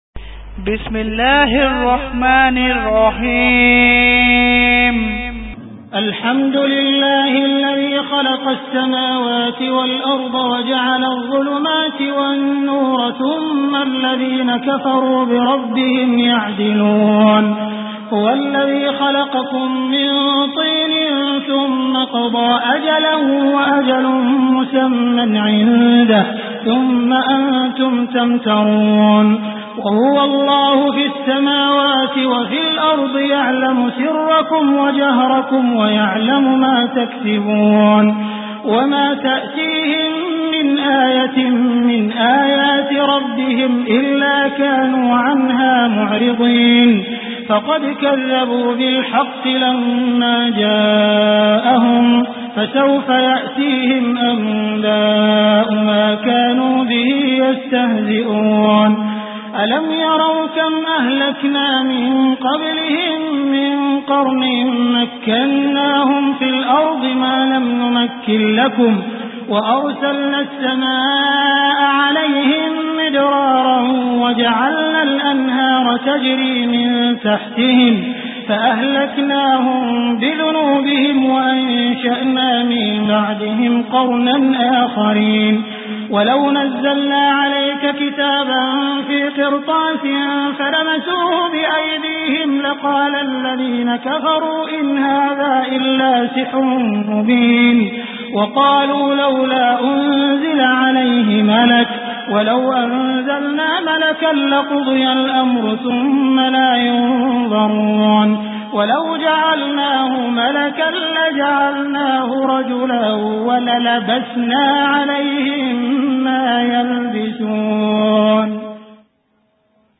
Surah Al Anam Beautiful Recitation MP3 Download By Abdul Rahman Al Sudais in best audio quality.